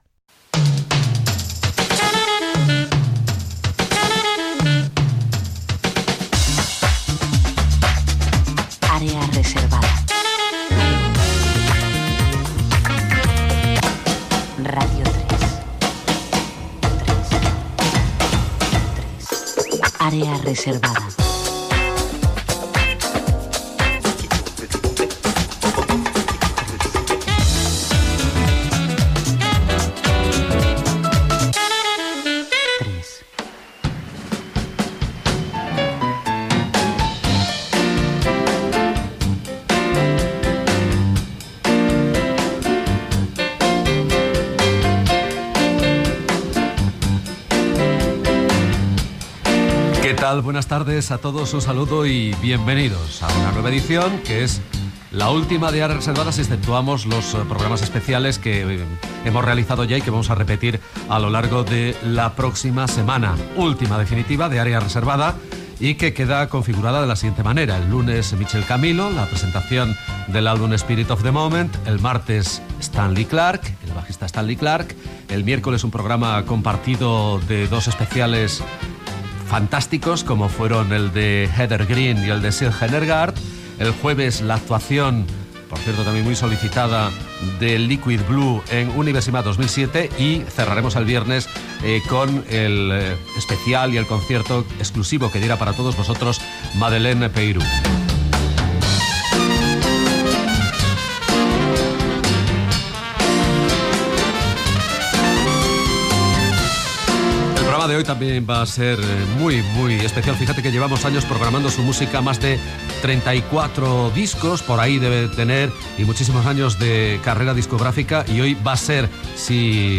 Careta del programa, presentació de l'últim programa.Sumari del programa, tema musical i comentari sobre els músics que l'han interpretat
Musical
FM